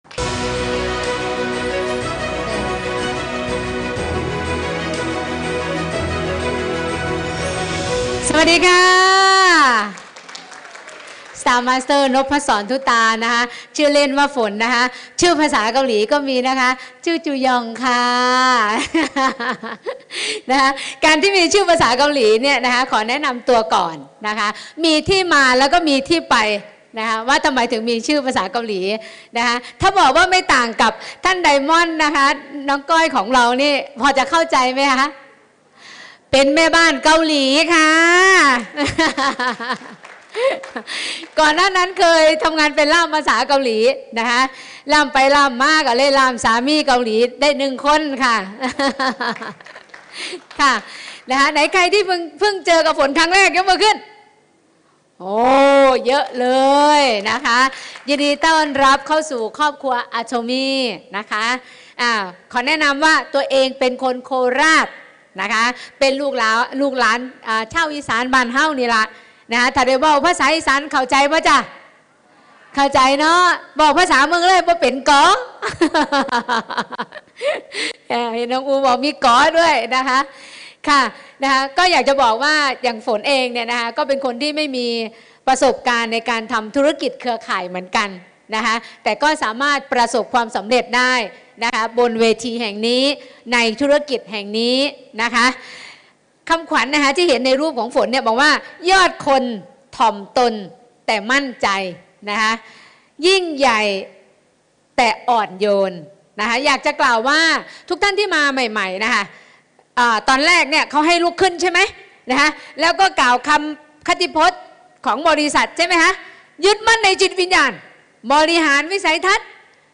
แบ่งปันประสบการณ์
(ภาษาอีสาน)